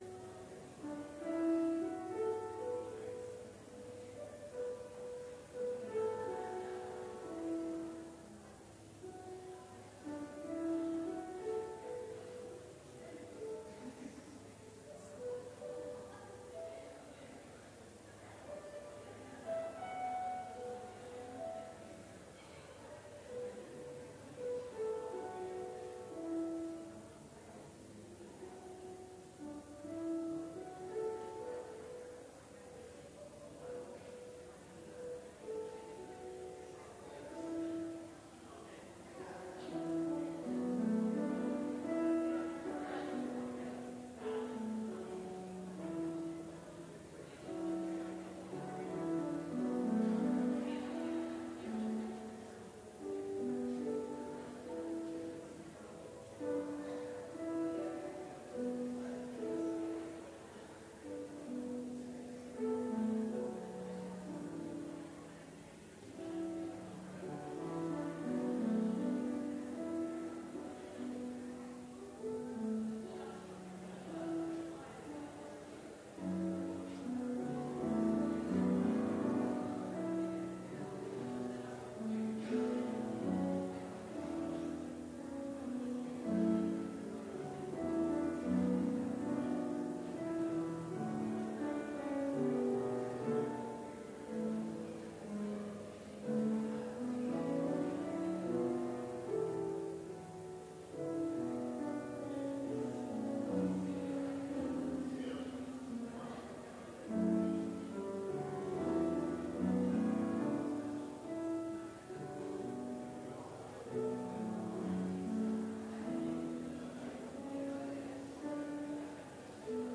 Complete service audio for Chapel - January 18, 2019